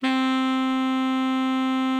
Added more instrument wavs
bari_sax_060.wav